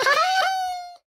Minecraft Version Minecraft Version 1.21.5 Latest Release | Latest Snapshot 1.21.5 / assets / minecraft / sounds / mob / cat / ocelot / death1.ogg Compare With Compare With Latest Release | Latest Snapshot